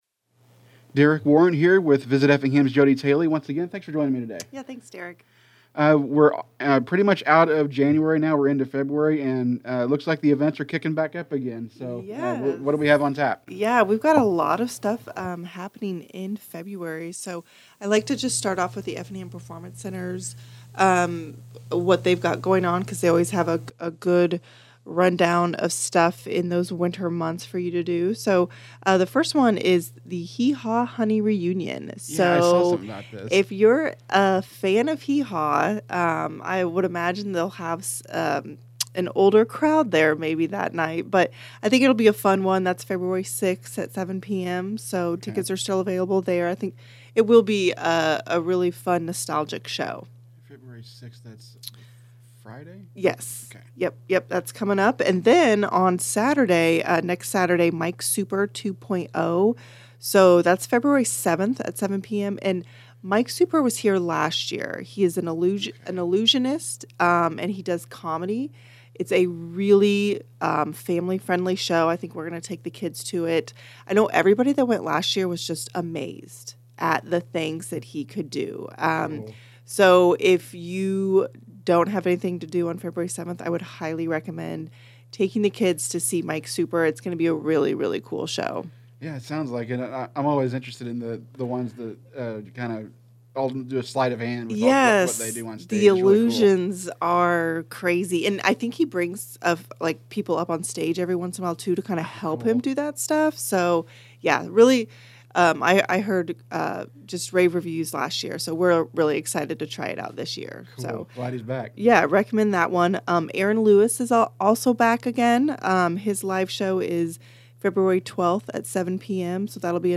This is part of a monthly series of interviews that will continue next month.